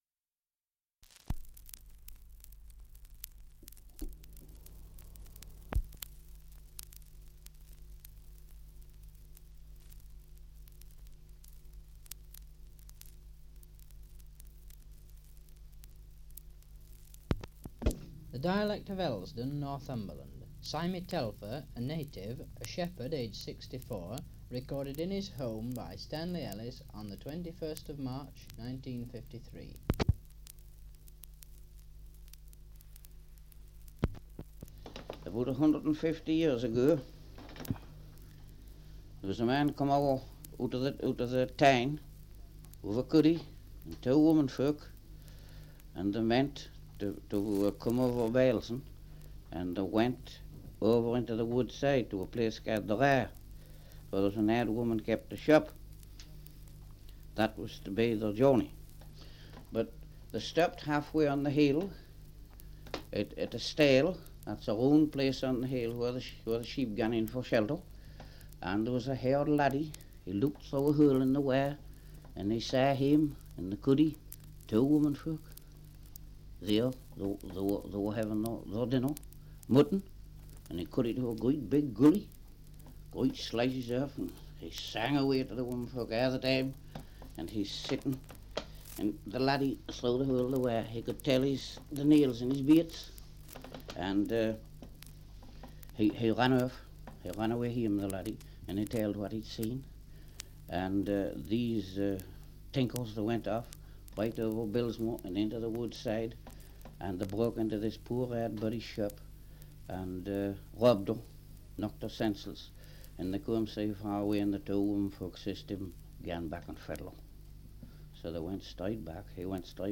1 - Survey of English Dialects recording in Elsdon, Northumberland. Survey of English Dialects recording in Wark, Northumberland
78 r.p.m., cellulose nitrate on aluminium